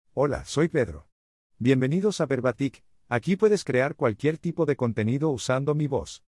Pedro — Male US Spanish AI voice
Pedro is a male AI voice for US Spanish.
Voice sample
Listen to Pedro's male US Spanish voice.
Pedro delivers clear pronunciation with authentic US Spanish intonation, making your content sound professionally produced.